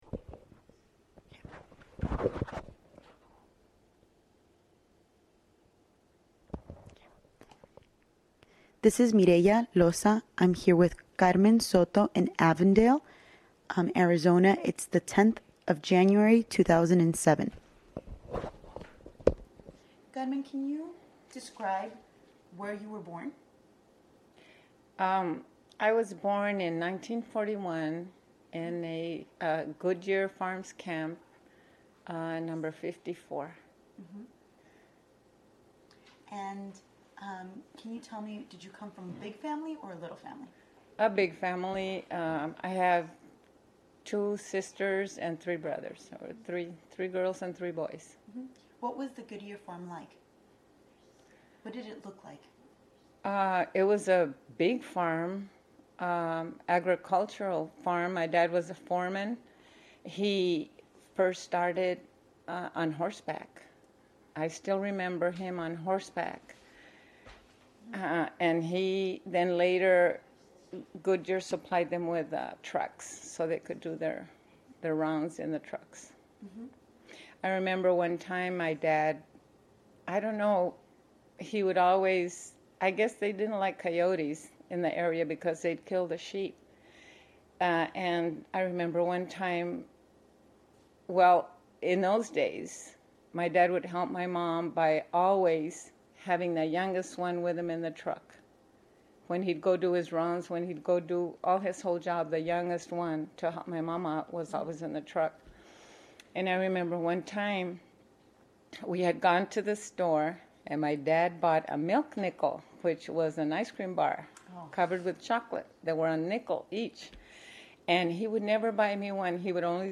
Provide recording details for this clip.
Location Avondale, Arizona